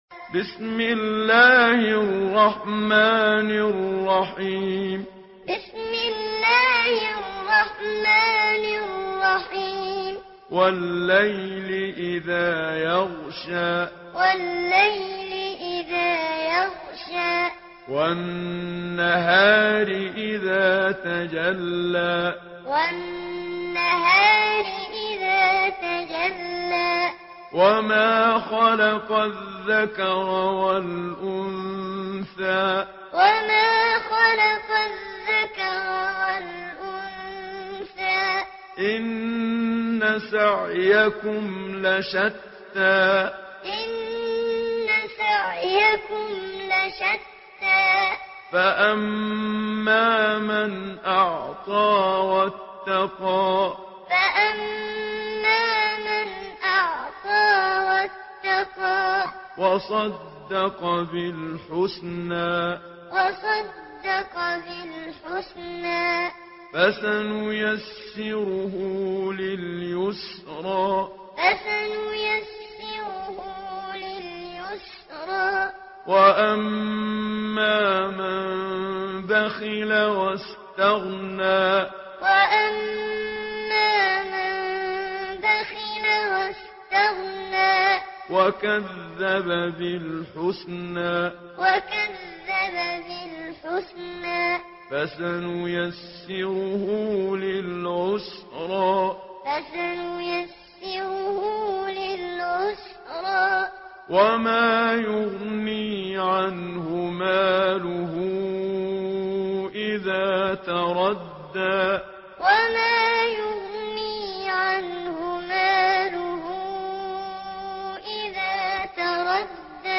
Surah Leyl MP3 in the Voice of Muhammad Siddiq Minshawi Muallim in Hafs Narration
Surah Leyl MP3 by Muhammad Siddiq Minshawi Muallim in Hafs An Asim narration.